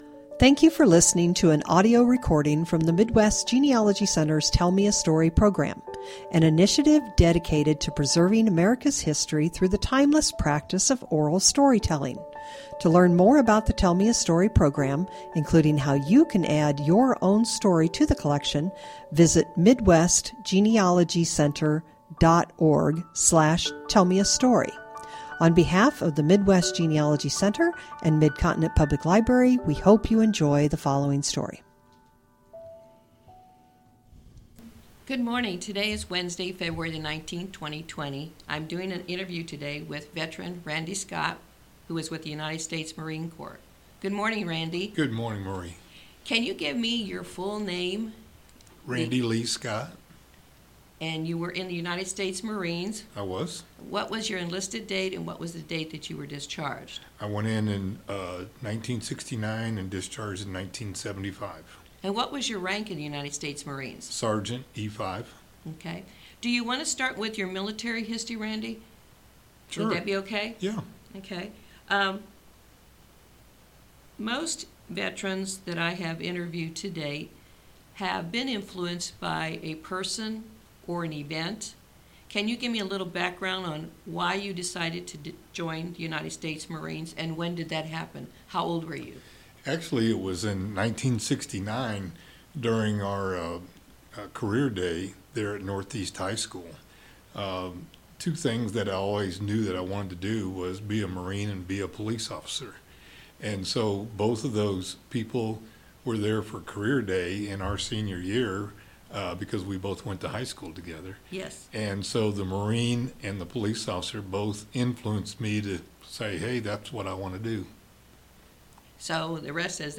Veteran's Salute Oral History Recordings